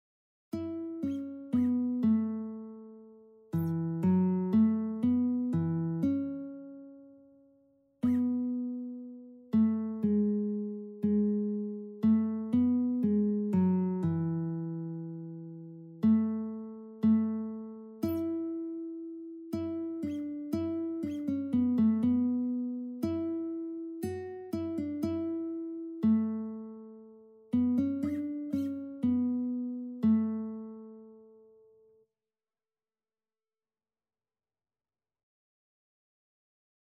Allegro (View more music marked Allegro)
4/4 (View more 4/4 Music)
Classical (View more Classical Lead Sheets Music)